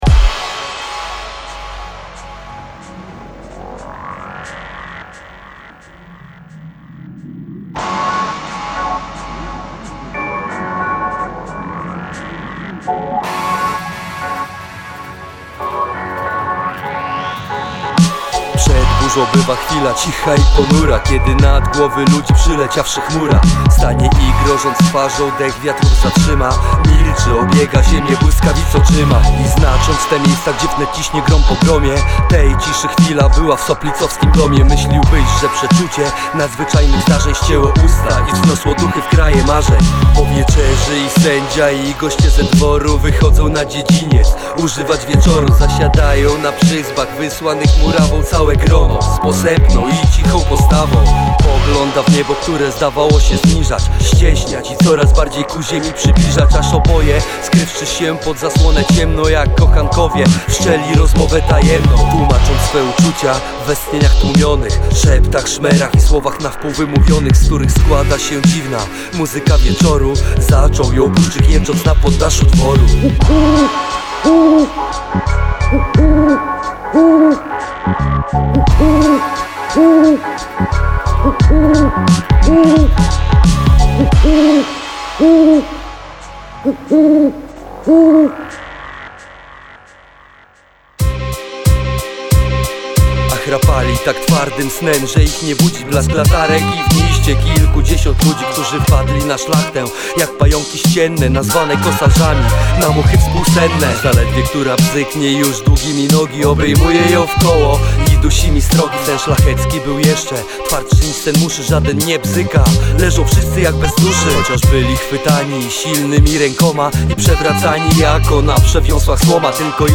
hop-hopowa wersja fragmentów naszej narodowej epopei.
A my nawet śpiewamy.